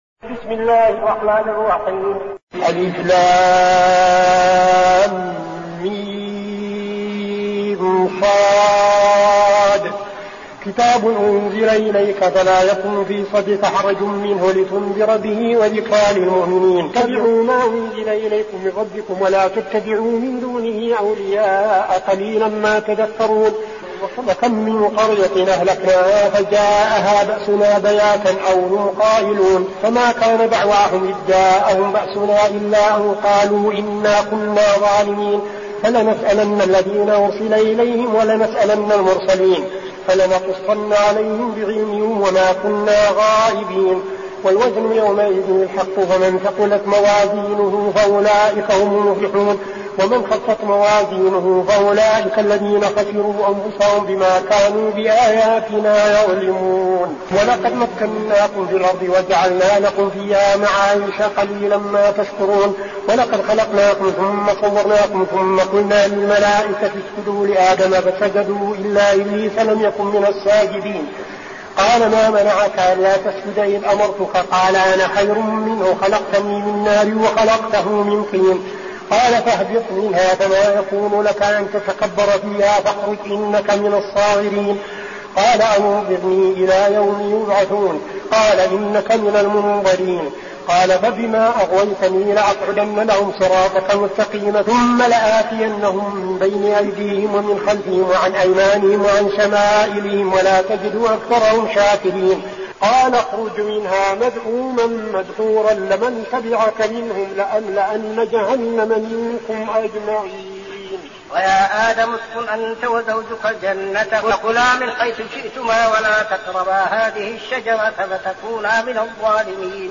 المكان: المسجد النبوي الشيخ: فضيلة الشيخ عبدالعزيز بن صالح فضيلة الشيخ عبدالعزيز بن صالح الأعراف